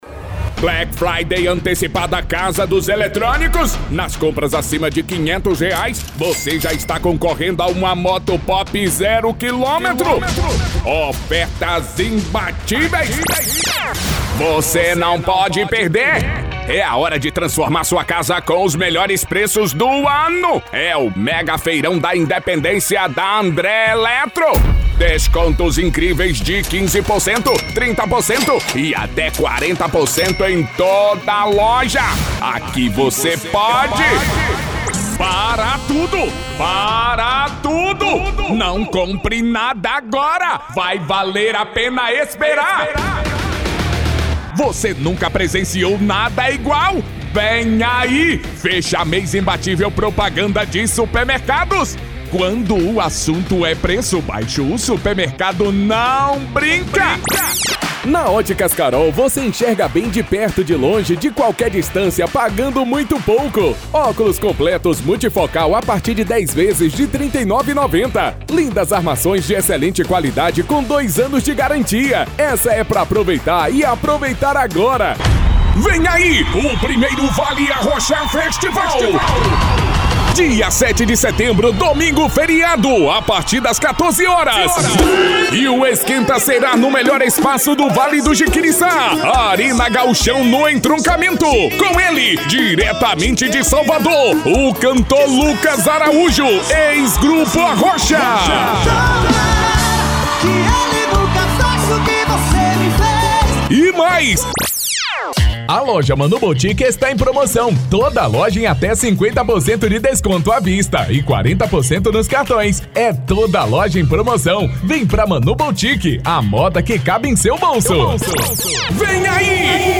Spot Comercial
Vinhetas
VT Comercial
Padrão
Impacto
Animada